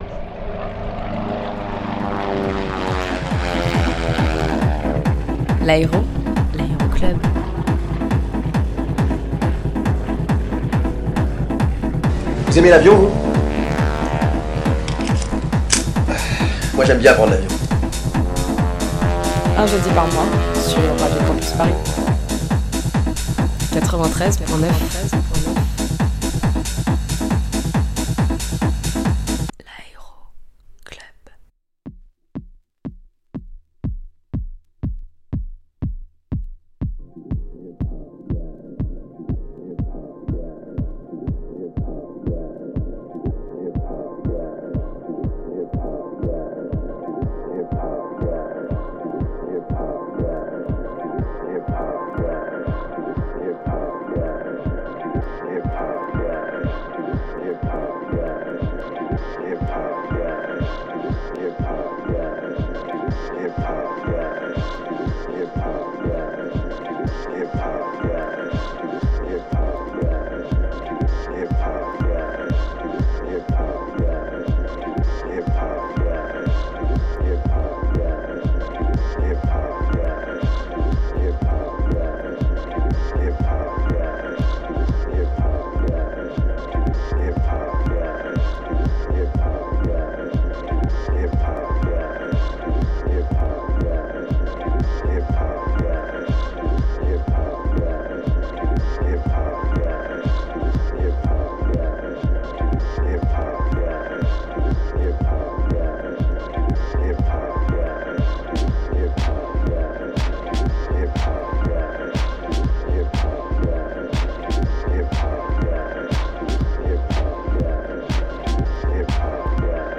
Mix Électro